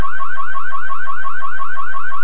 Dual-Tone 15-Watt Siren
Dual tone siren - steady and warble
Sound output: 115 dB @ 12 VDC
Hear it: Steady